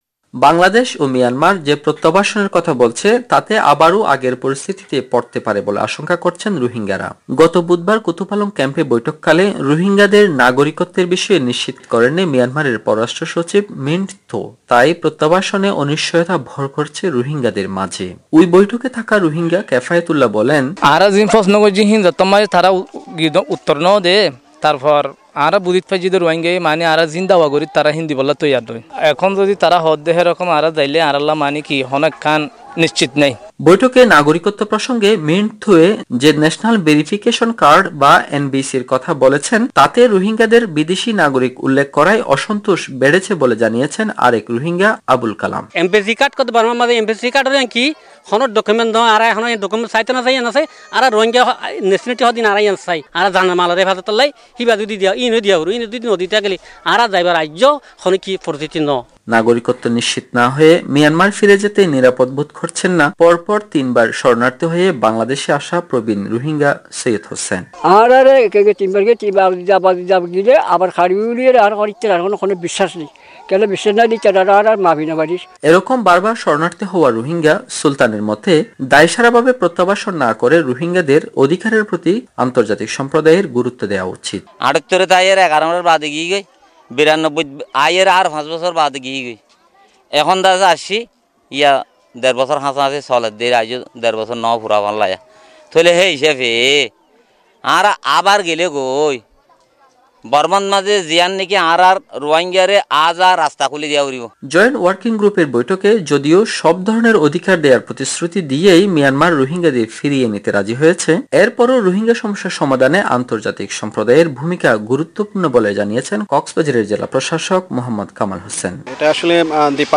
কক্সবাজার থেকে